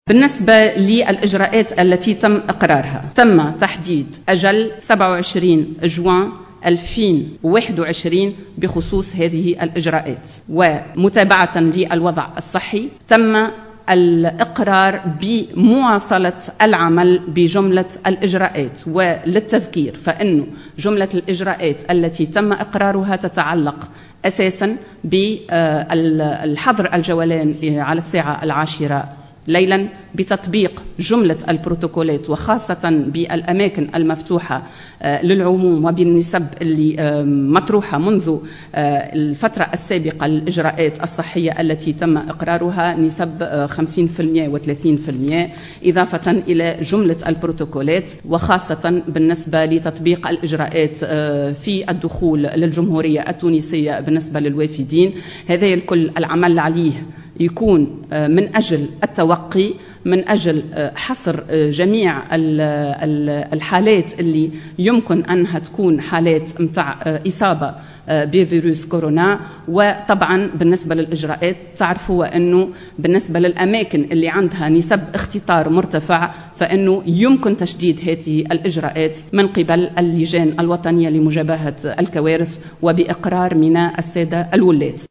أكدّت الناطقة الرسمية باسم الحكومة حسناء بن سليمان، خلال ندوة صحفية عُقدت بالقصبة، أن اللجنة الوطنية لمجابهة وباء الكورونا، أقرّت مواصلة العمل بجملة الإجراءات السابقة، إلى تاريخ 27 جوان الحالي.